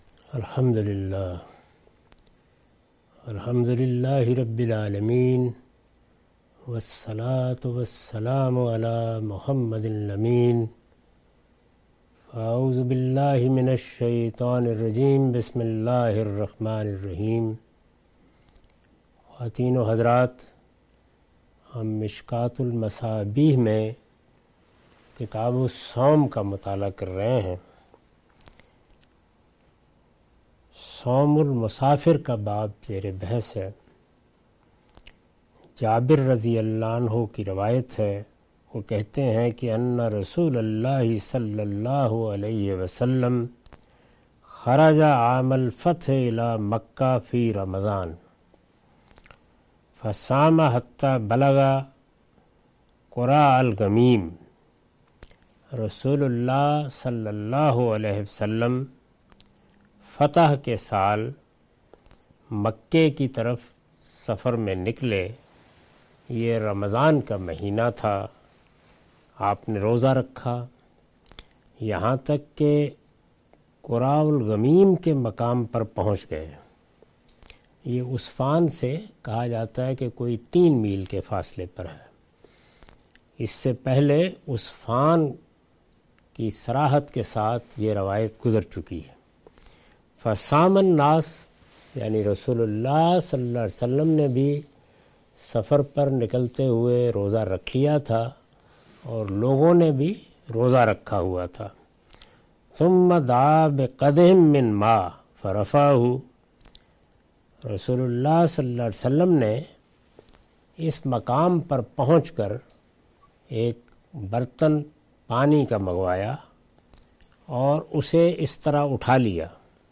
Sayings of the Prophet , Questions & Answers